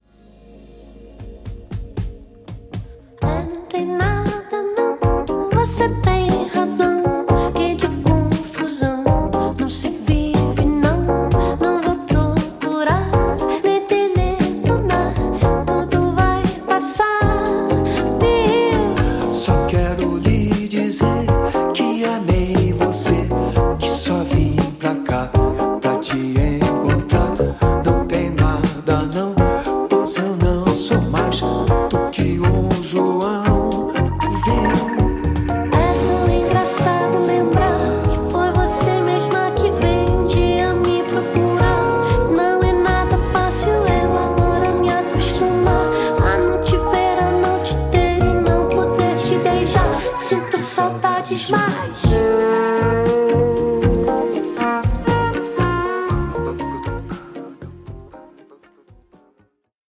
どちらもバンド・サウンドの緩やかなグルーヴも相まってリラックスした仕上がり